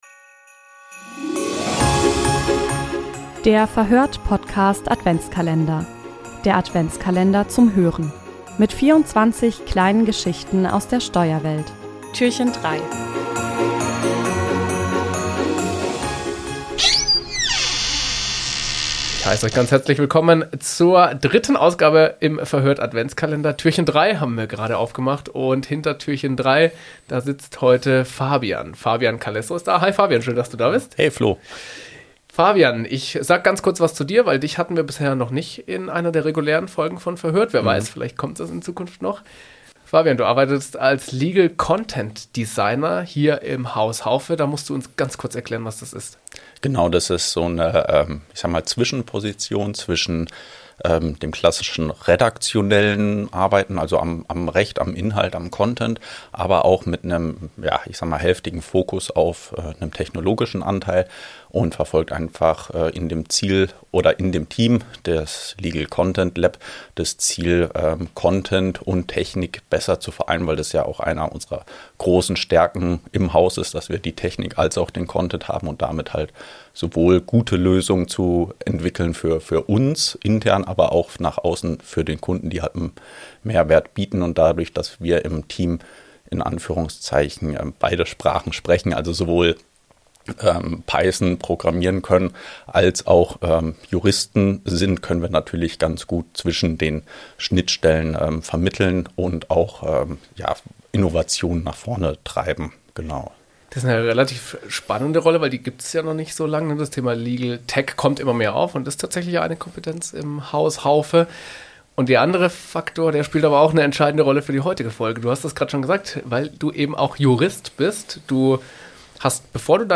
Im Verhör(t) Podcast-Adventskalender erzählen Steuerexpertinnen und Steuerexperten Geschichten und Anekdoten aus ihrem Alltag.